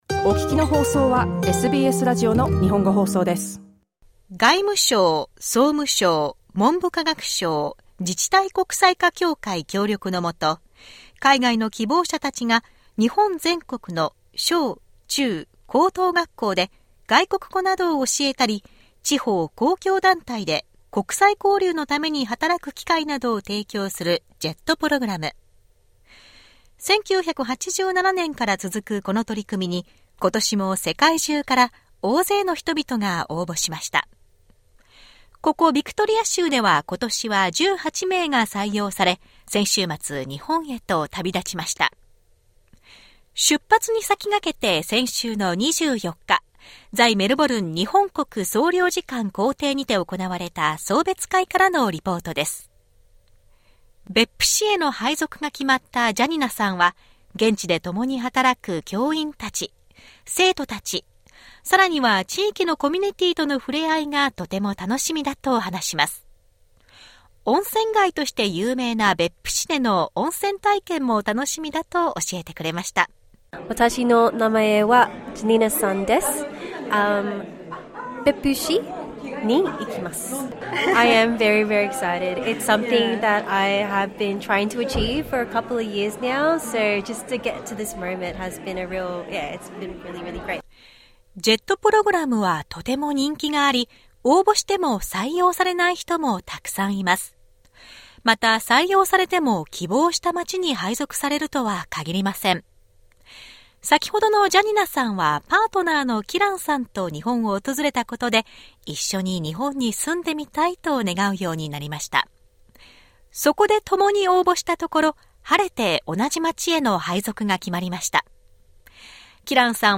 本年度のJETプログラム参加者たちの出発に先駆け、在メルボルン総領事公邸にて行われた送別会からのレポートです。